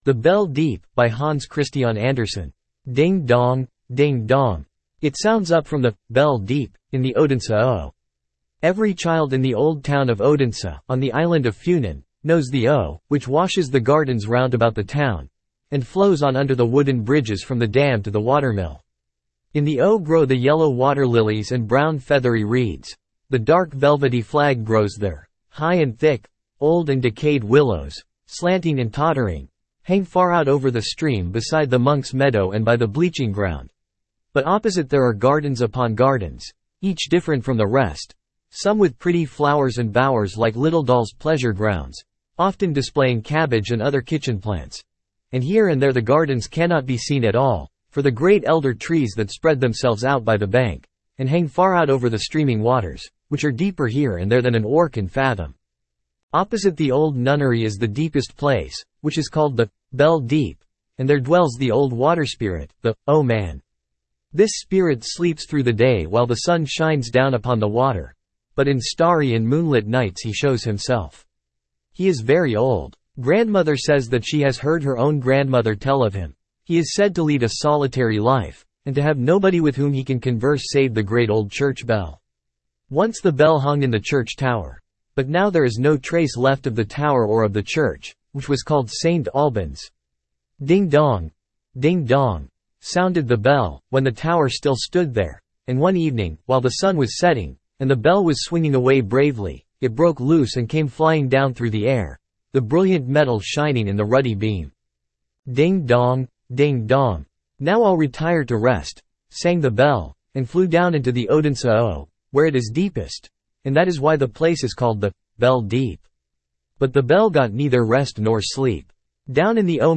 Standard (Male)